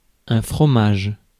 Ääntäminen
Synonyymit fromton frometon Ääntäminen France (Paris): IPA: [ɛ̃ fʁɔ.maʒ] Tuntematon aksentti: IPA: /fʁɔ.maʒ/ Haettu sana löytyi näillä lähdekielillä: ranska Käännös Ääninäyte Substantiivit 1. cheese US Suku: m .